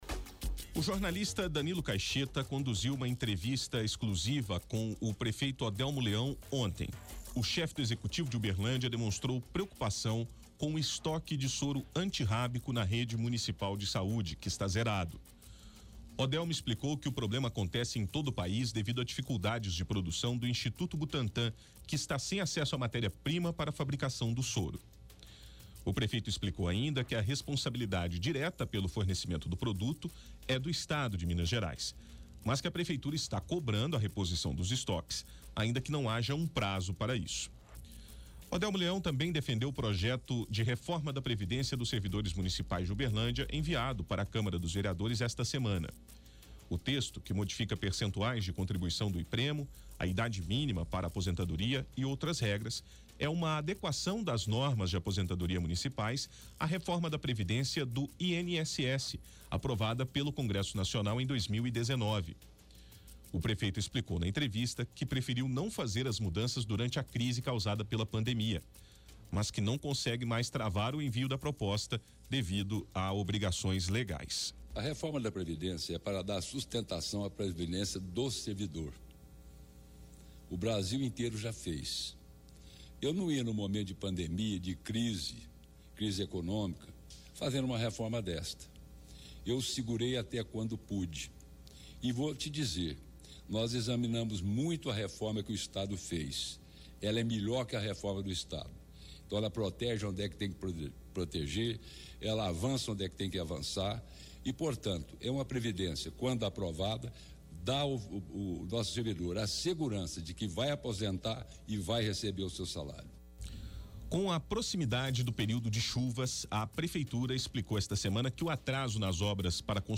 entrevista-exclusiva-com-o-prefeito-Odelmo-ontem.mp3